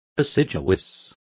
Complete with pronunciation of the translation of assiduous.